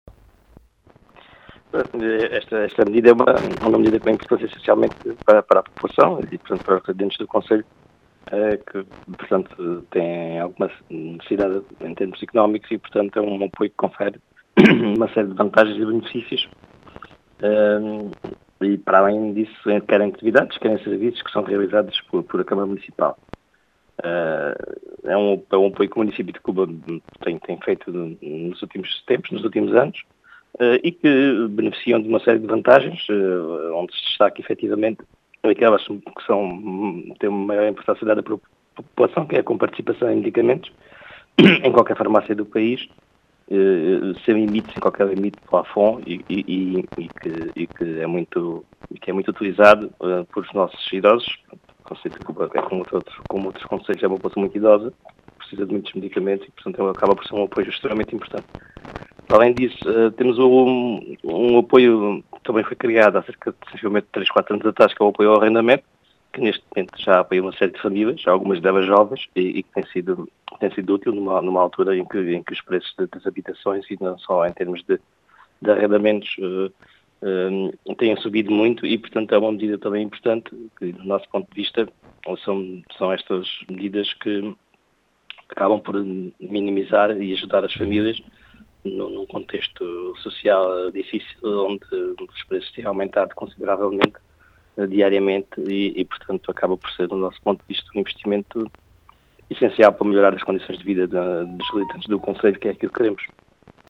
As explicações são do presidente da Câmara Municipal de Cuba, João Português, que fala num “investimento essencial” para melhorar a vida no concelho.